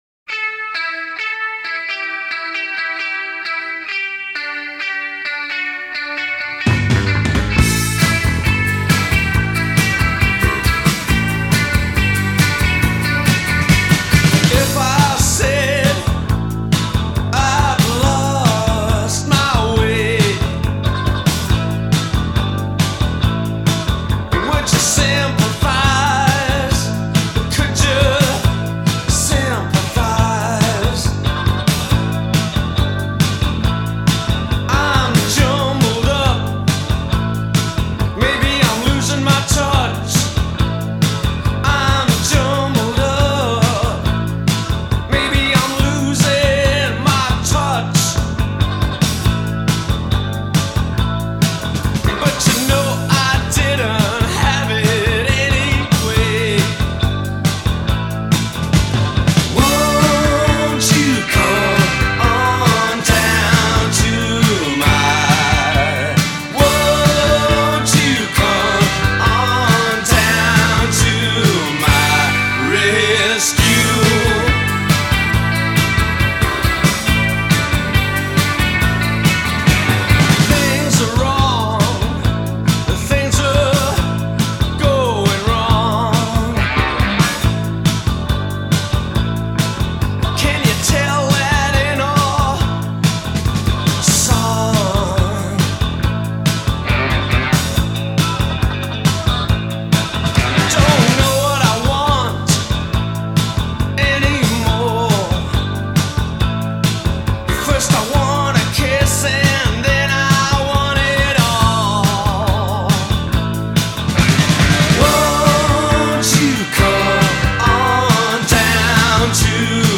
featuring one of the best guitar hooks
“college rock”